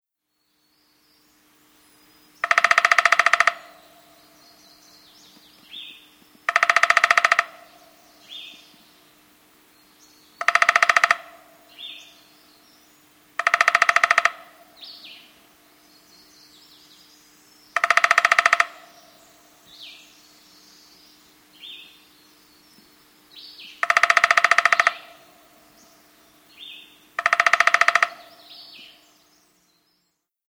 Vogel Nummer 1 hat ein markantes Hörmerkmal.